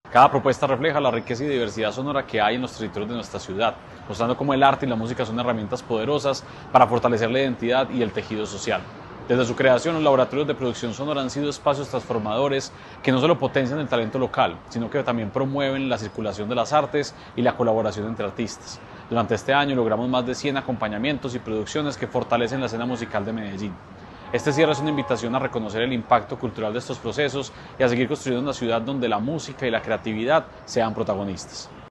Palabras de Santiago Silva, secretario de Cultura Ciudadana ¿A qué suena Medellín?